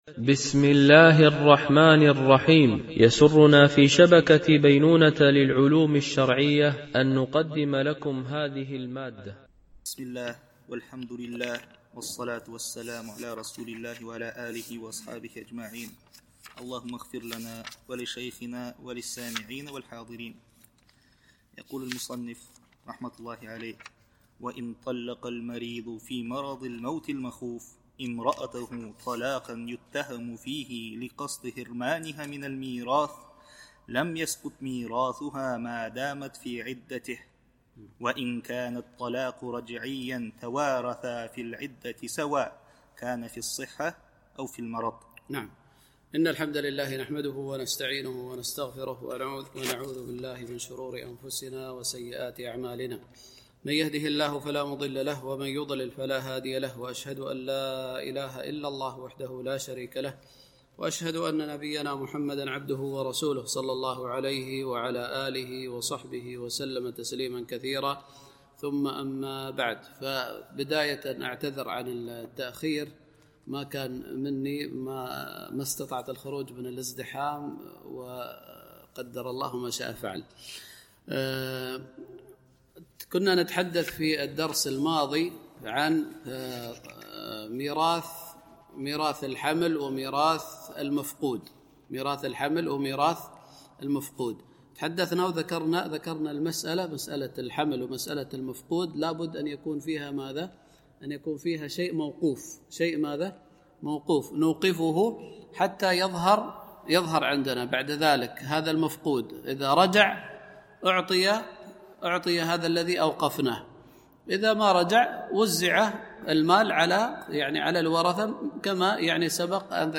شرح عمدة الفقه ـ الدرس 111 (كتاب الفرائض)